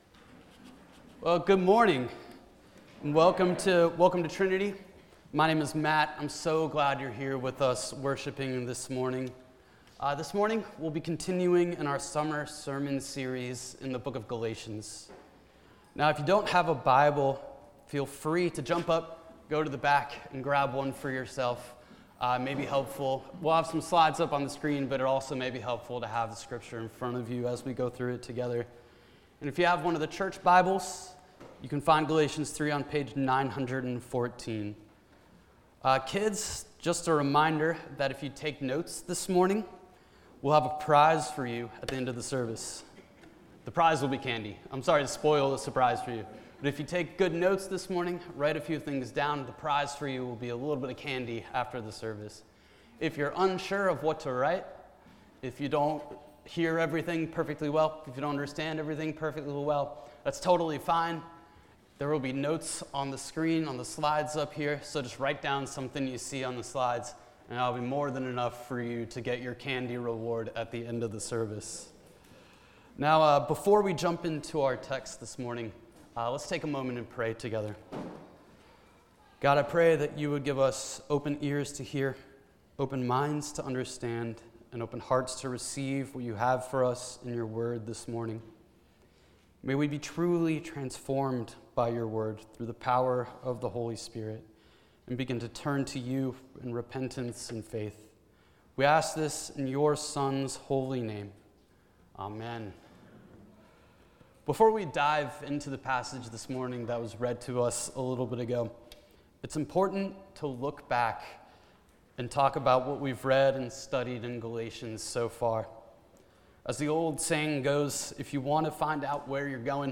preaching on Galatians 3:15-22.